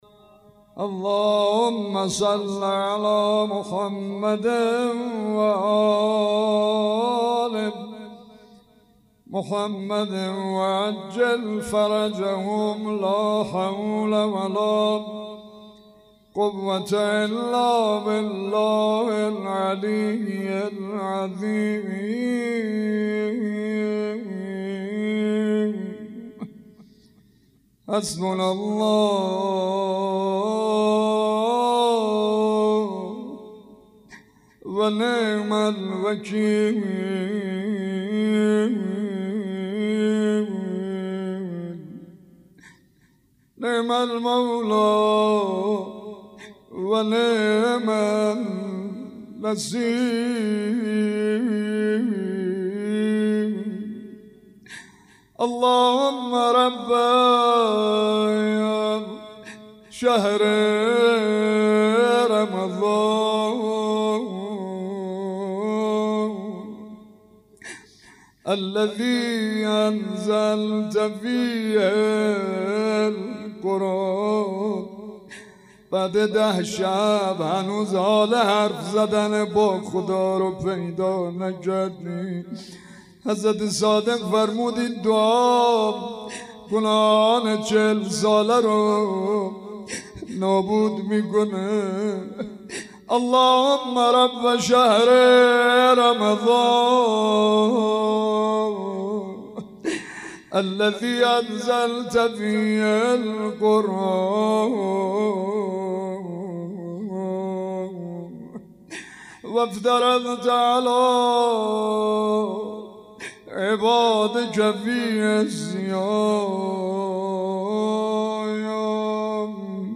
روضه و مناجات
مناسبت : شب نهم رمضان
قالب : روضه مناجات